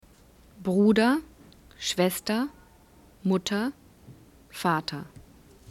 vocalic R (German) : pronounced as vowel
The German vocalic 'r' is so-called because it is pronounced as a vowel, not a consonant.
Sounds 1: Vocalic 'r' in final position: 83.mp3